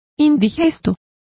Complete with pronunciation of the translation of stodgy.